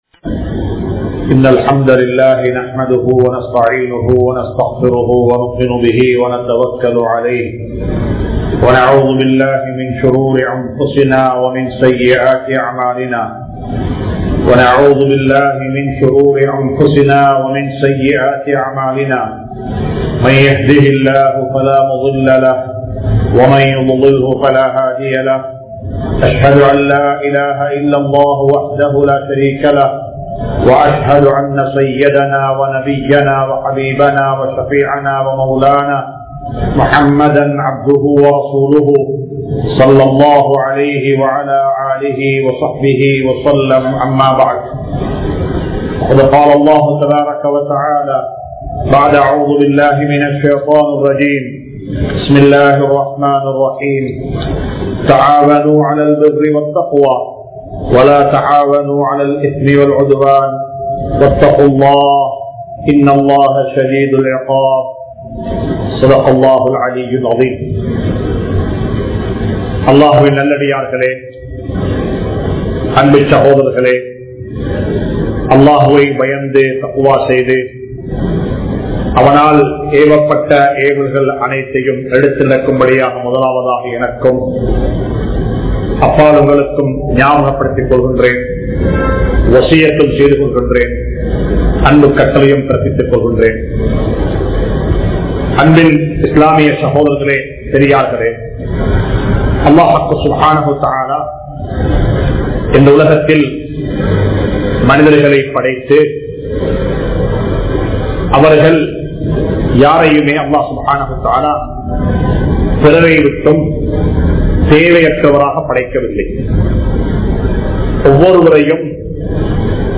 Pirarin Urimaihalai Parikkaatheerhal(பிறரின் உரிமைகளை பறிக்காதீர்கள்) | Audio Bayans | All Ceylon Muslim Youth Community | Addalaichenai
Colombo04,Bambalapitiya, Muhiyadeen Jumua Masjith